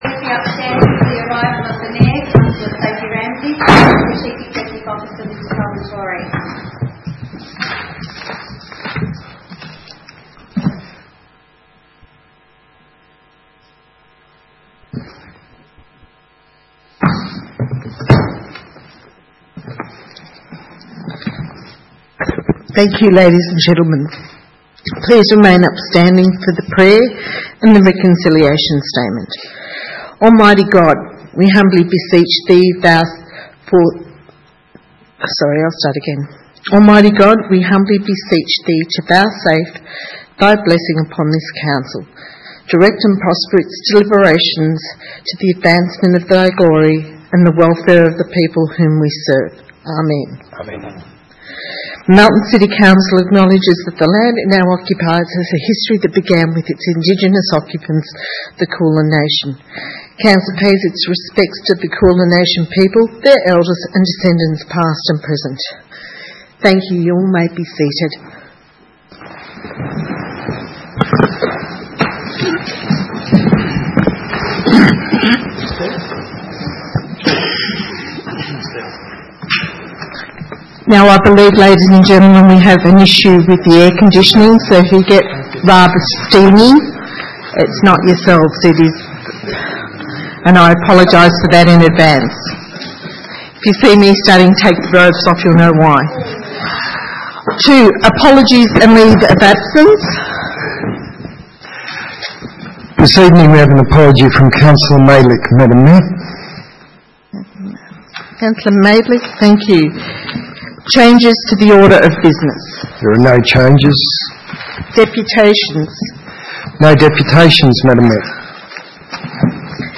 6 February 2017 - Ordinary Council Meeting